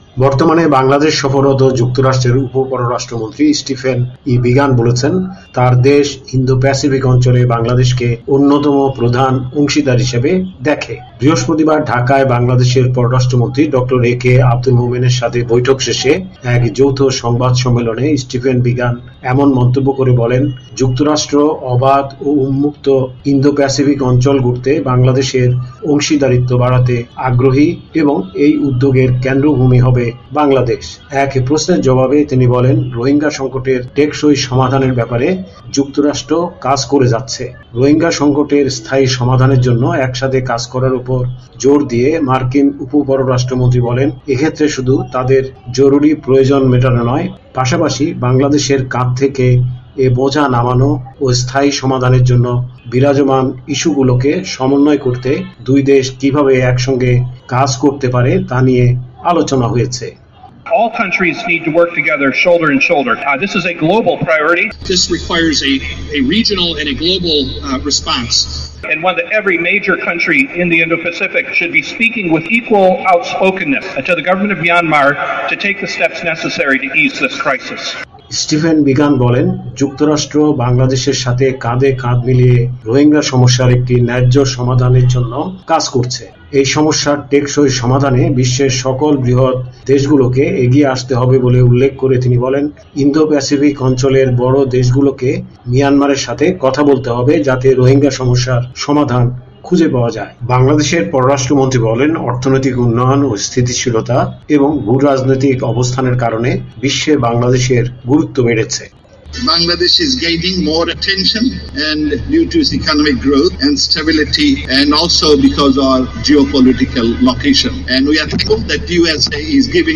বর্তমানে বাংলাদেশ সফররত যুক্তরাষ্ট্রের উপ পররাষ্ট্রমন্ত্রী স্টিফেন ই বিগান বলেছেন তাঁর দেশ ইন্দো-প্যাসিফিক অঞ্চলে বাংলাদেশকে অন্যতম প্রধান অংশীদার হিসেবে দেখে। বৃহস্পতিবার ঢাকায় বাংলাদেশের পররাষ্ট্রমন্ত্রী ড. এ কে আব্দুল মোমেনের সাথে বৈঠক শেষে এক যৌথ সংবাদ সম্মেলনে স্টিফেন বিগান এমন মন্তব্য করে বলেন